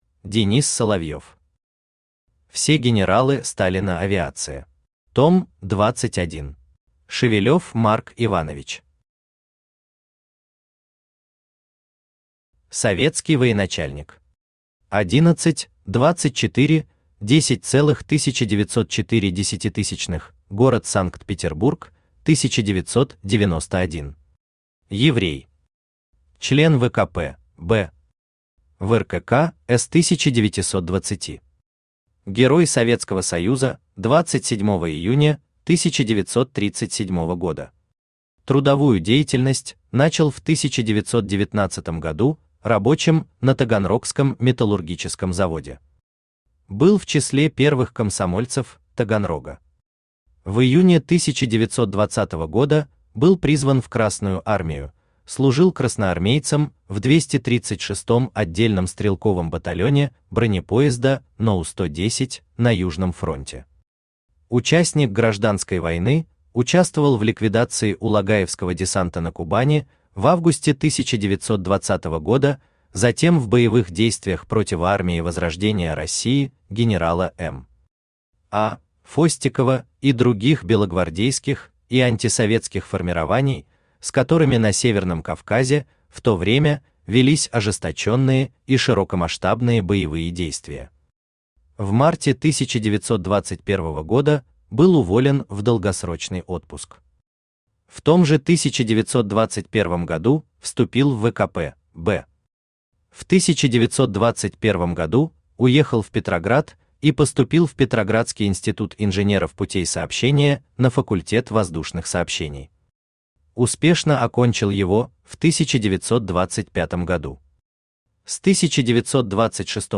Аудиокнига Все генералы Сталина Авиация. Том 21 | Библиотека аудиокниг
Том 21 Автор Денис Соловьев Читает аудиокнигу Авточтец ЛитРес.